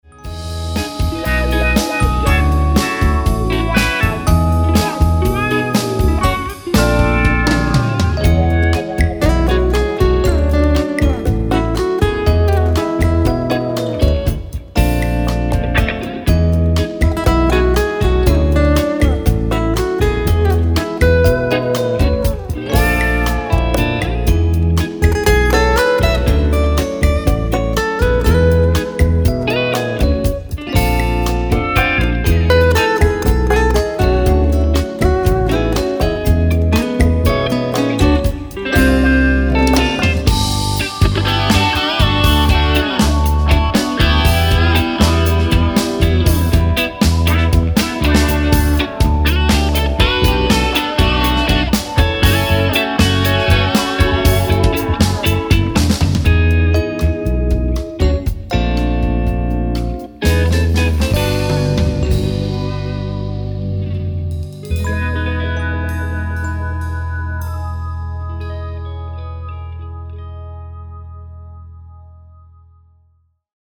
guitars, percussion
drums
bass
keyboards